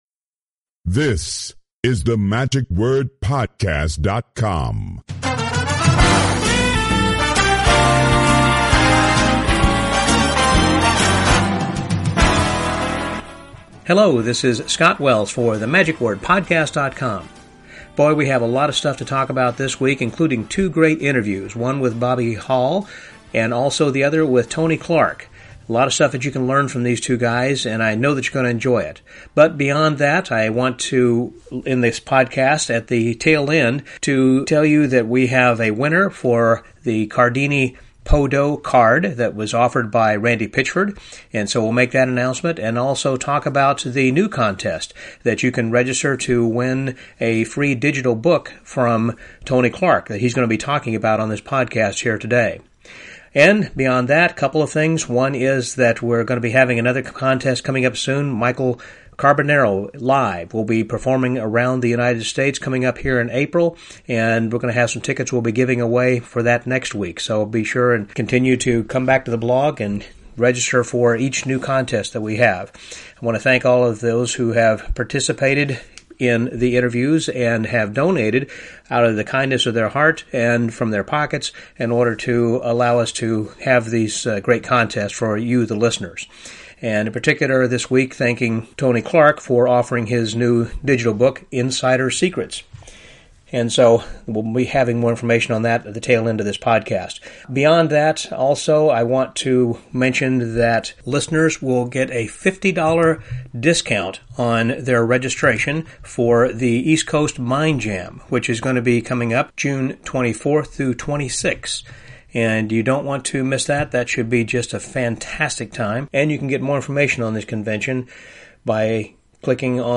Live at the Loft.mp3